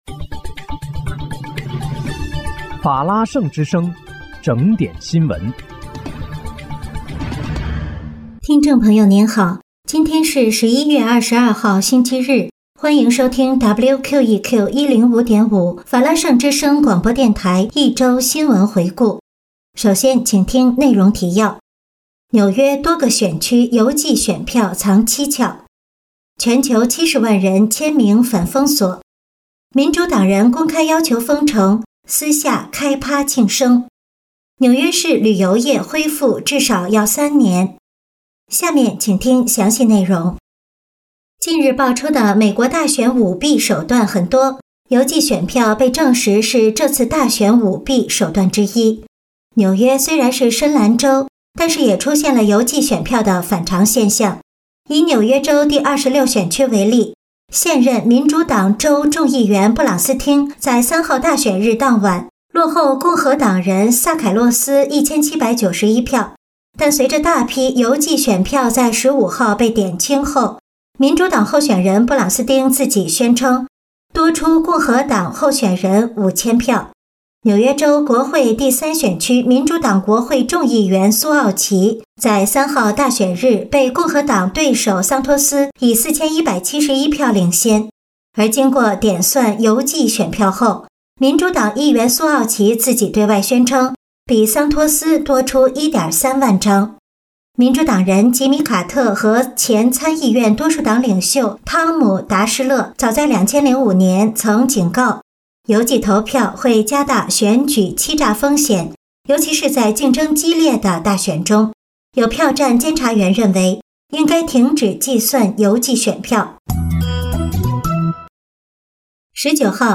11月22日（星期日）一周新闻回顾